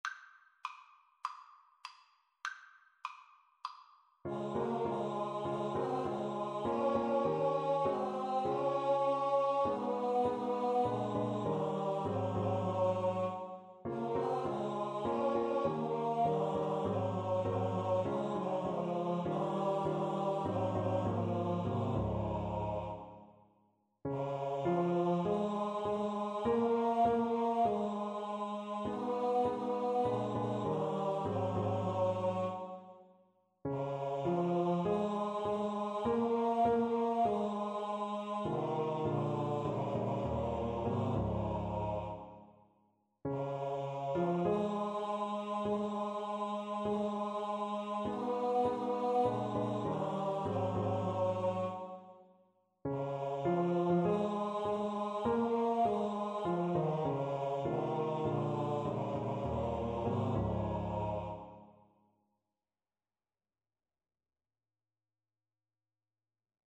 Christmas Christmas Choir Sheet Music Rise Up, Shepherd, and Follow
4/4 (View more 4/4 Music)
Db major (Sounding Pitch) (View more Db major Music for Choir )
Choir  (View more Easy Choir Music)
Traditional (View more Traditional Choir Music)